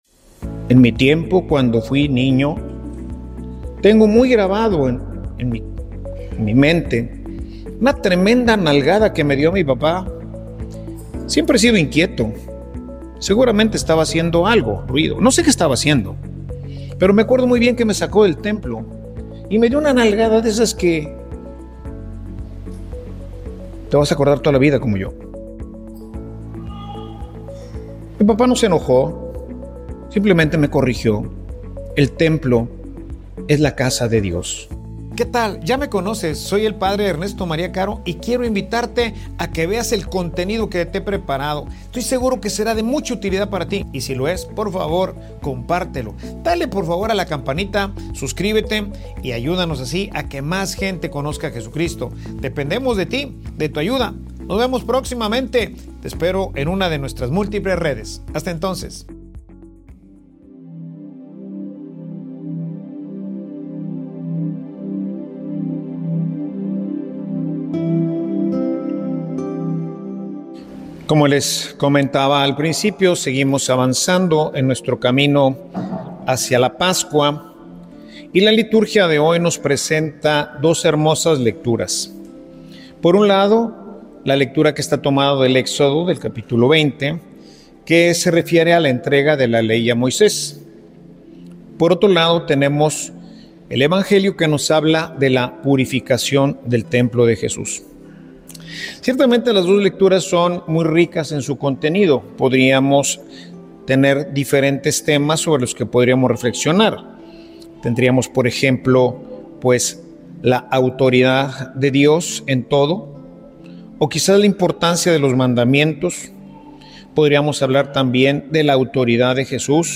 Homilia_Domingo_III_Cuaresma.mp3